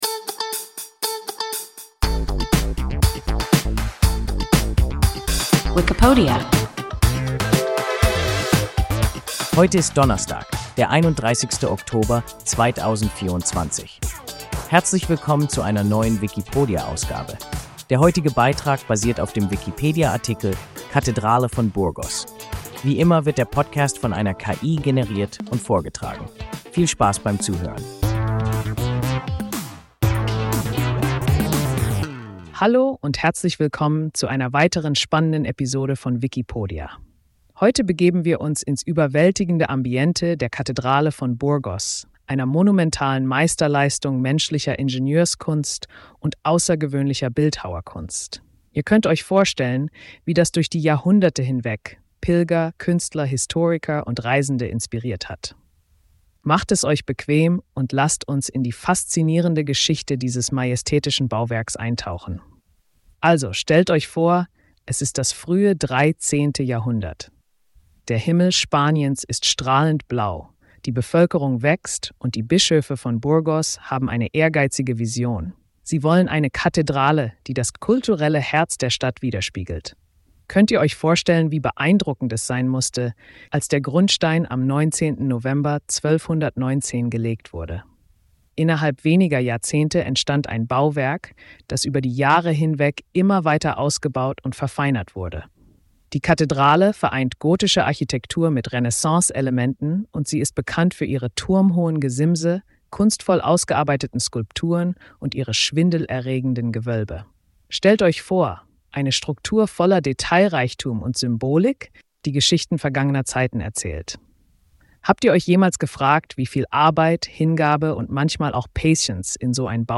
Kathedrale von Burgos – WIKIPODIA – ein KI Podcast